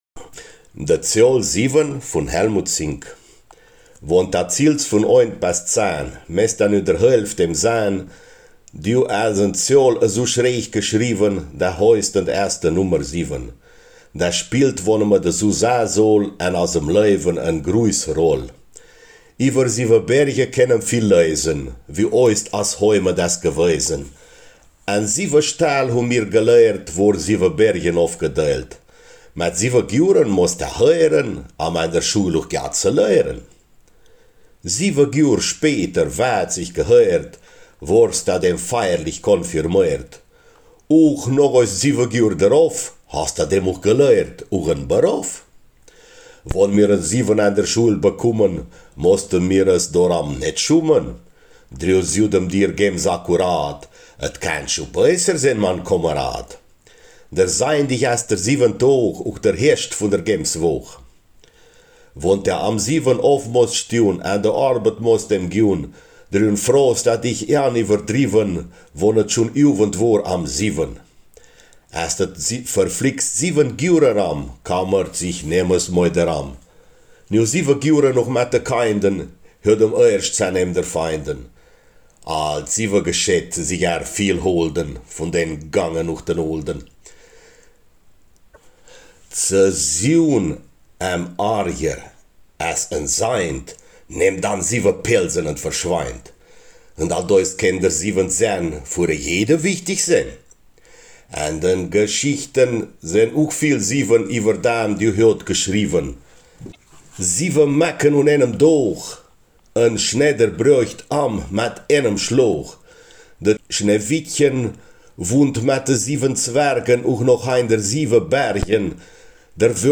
Im Gedicht „De Zeohl 7“ begegnet uns die Männermundart.
In Schenk und Umgebung wird -n in vielen Fällen als -m gesprochen, zum Beispiel: zäenemder oder Lemd (Land)
Ortsmundart: Großschenk